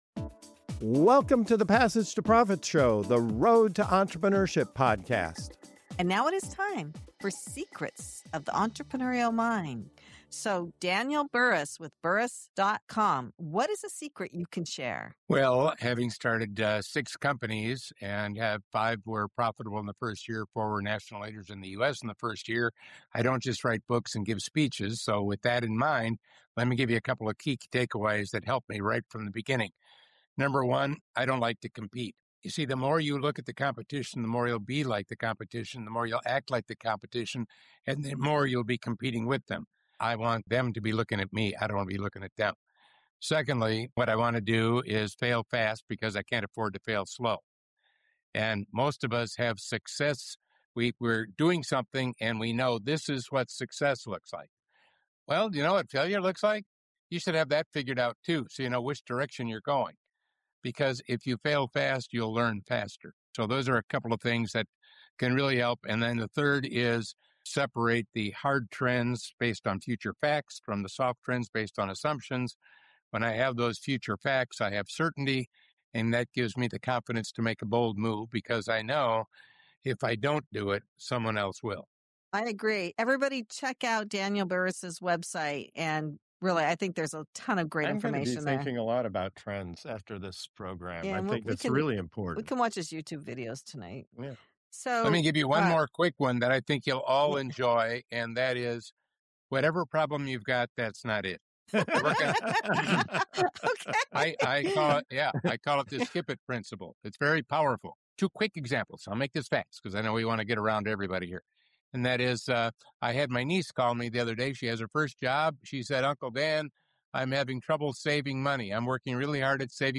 In this powerful Secrets of the Entrepreneurial Mind segment, top entrepreneurs reveal the unconventional strategies driving real success in today’s fast-changing world. Discover why avoiding competition can make you a market leader, how failing fast—sometimes in just two weeks—accelerates growth, and the game-changing difference between hard trends (future facts) and soft assumptions.